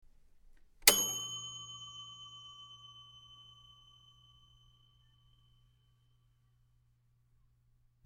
Oven Timer Ding 02
Oven_timer_ding_02.mp3